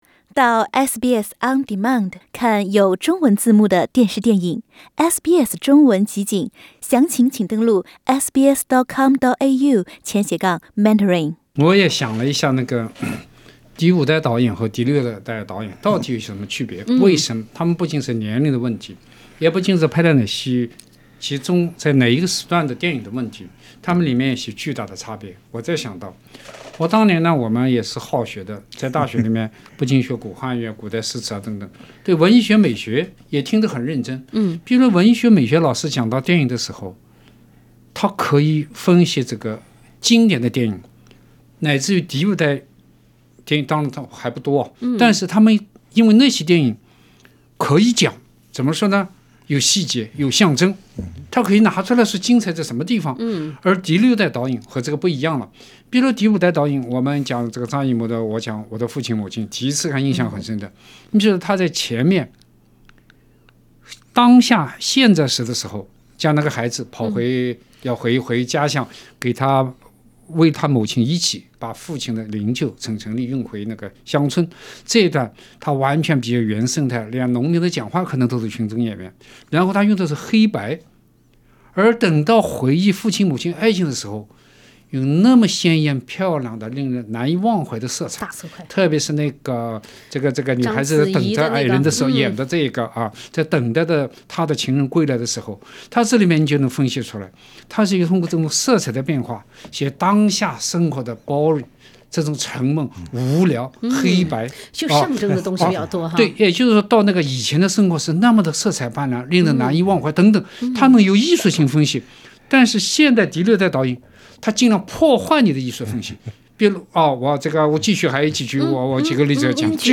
中國第五、第六代導演的代際標志是什麼？不同年代出生的三位《文化苦丁茶》三人組各有感受。
SBS電台《文化苦丁茶》每週五早上澳洲東部時間早上8:15播出，每週日早上8:15重播。